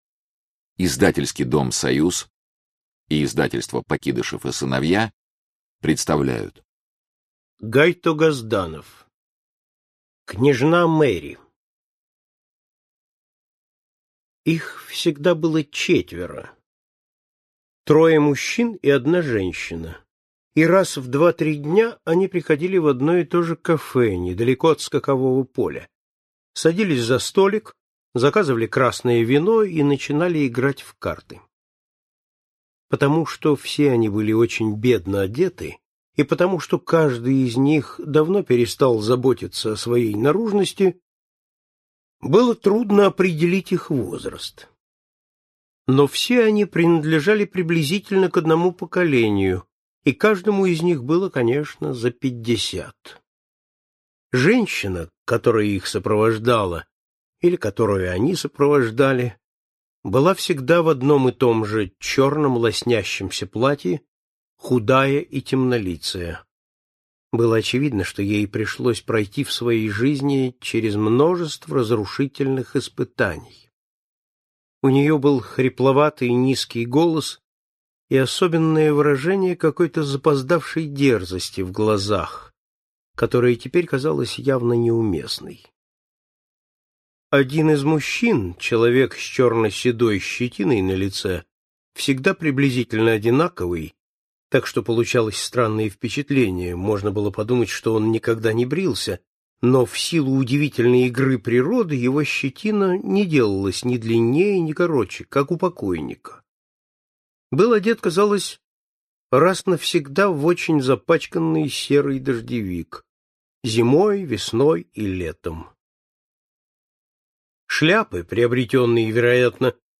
Аудиокнига Избранное. Рассказы | Библиотека аудиокниг